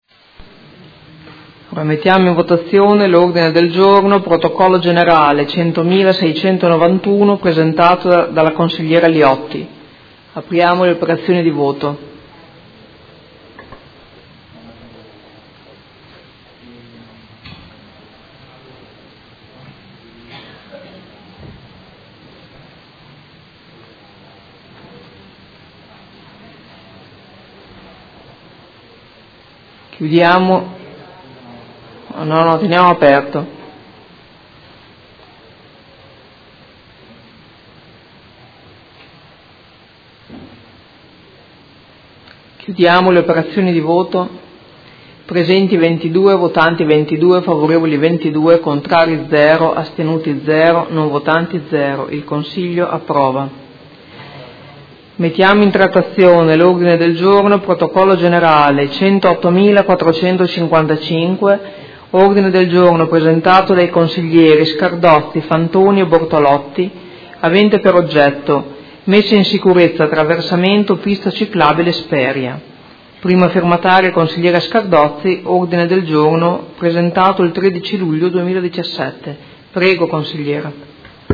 Presidentessa — Sito Audio Consiglio Comunale